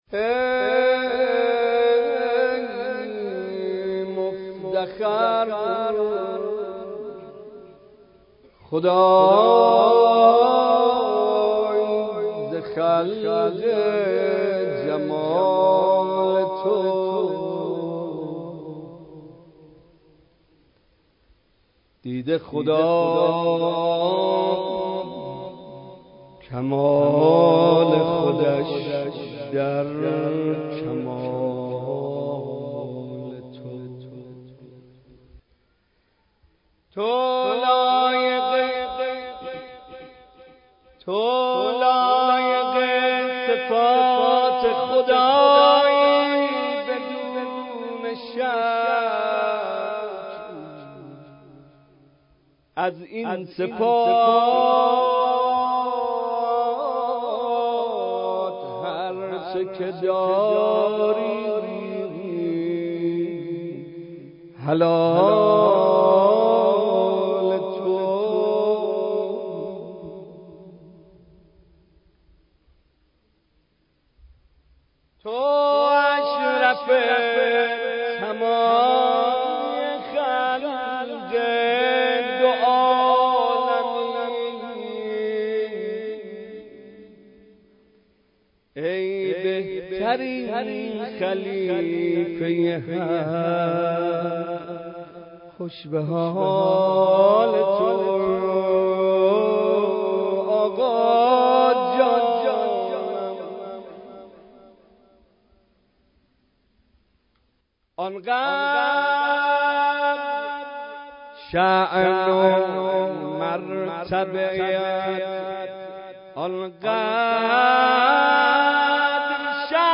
مداحی میلاد پیامبر (ص) و امام صادق(ع)